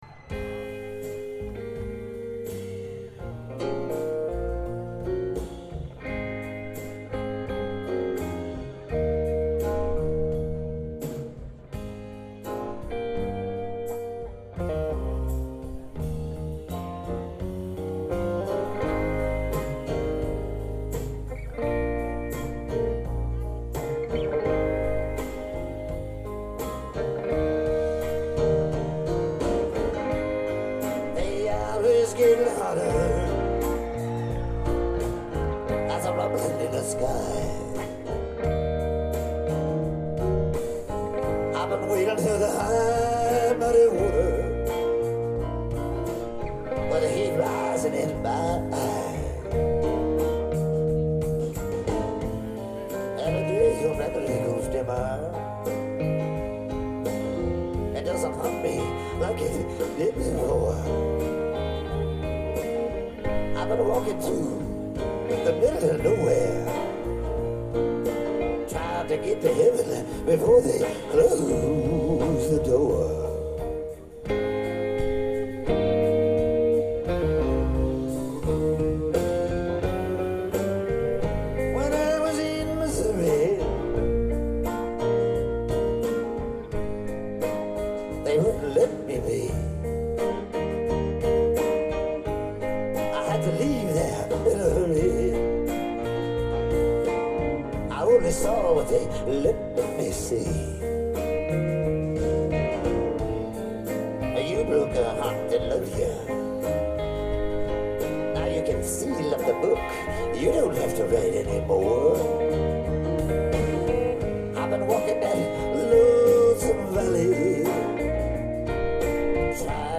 live in Budapest, 2003